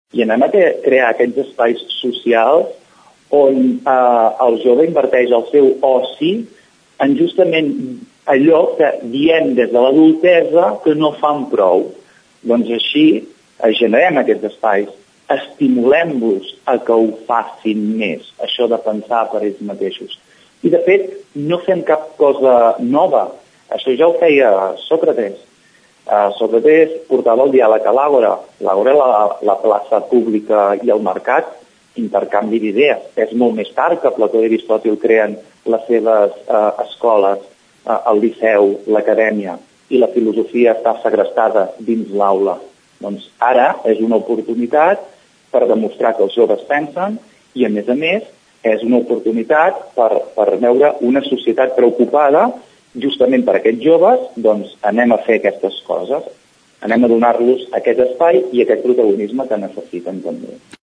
Ho explica en declaracions a Ràdio Tordera